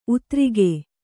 ♪ utrige